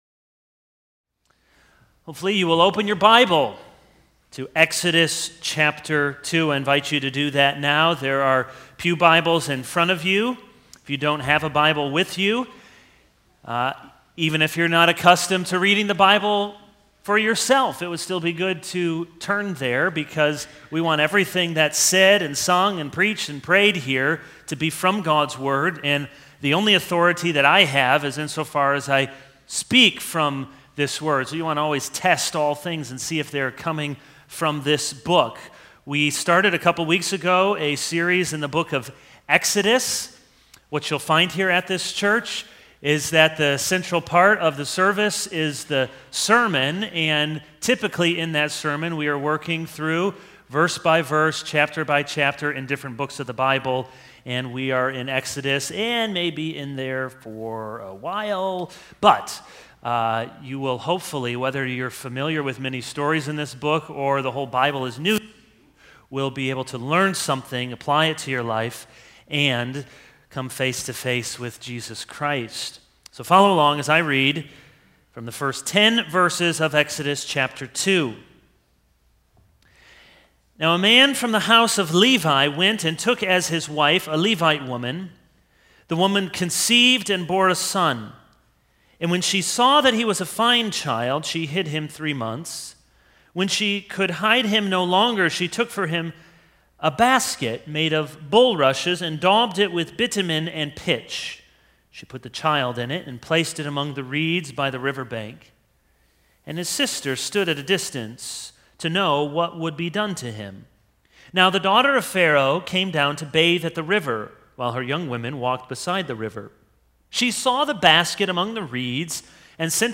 This is a sermon on Exodus 2:1-10.